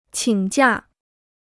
请假 (qǐng jià) Free Chinese Dictionary